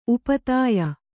♪ upatāya